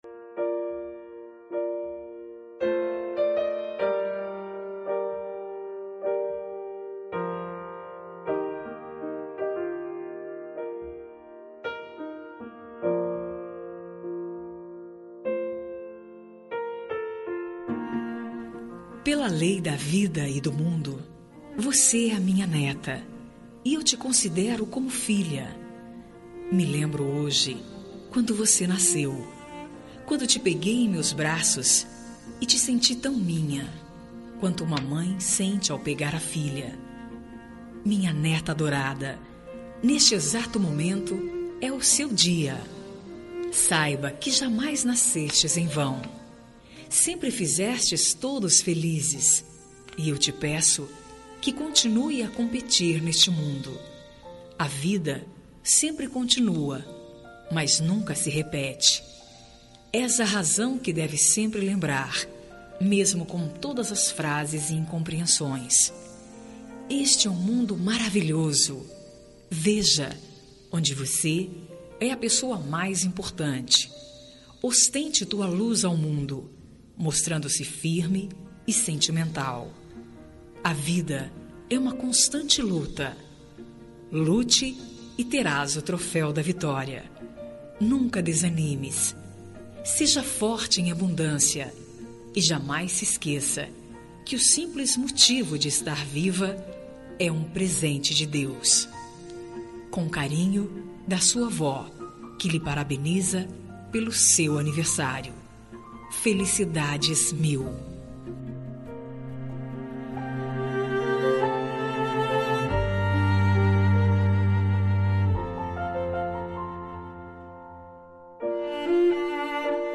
Aniversário de Neta – Voz Feminina – Cód: 131022